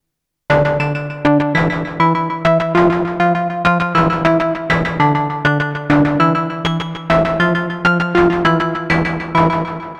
Novation Peak – Klangbeispiele
novation_peak_test__sync_rhythm.mp3